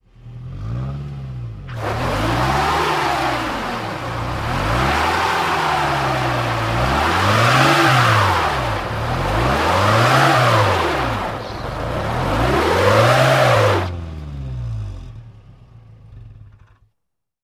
На этой странице собраны реалистичные звуки пробуксовки автомобиля в разных условиях: на льду, в грязи, на мокром асфальте.
Звук машины застрял в колее и не может выбраться